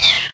yoshi_snore2.ogg